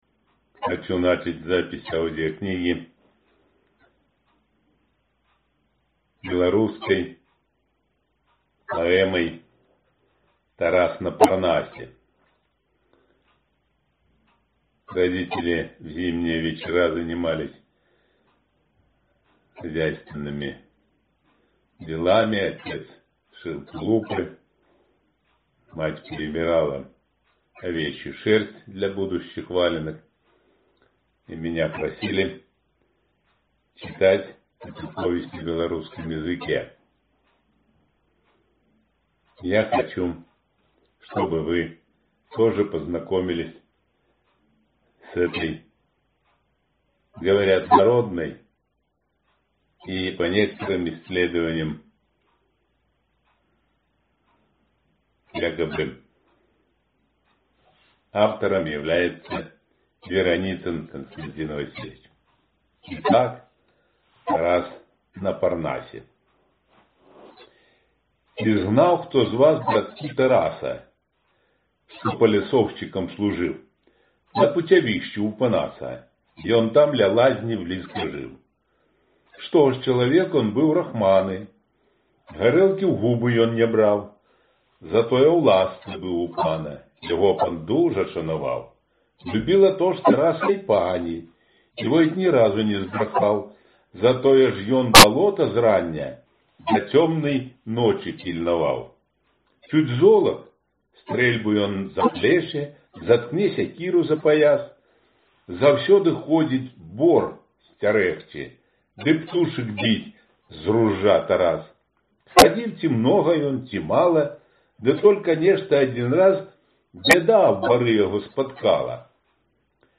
Аудиокнига Жизнь как полёт. Летопись военного летчика | Библиотека аудиокниг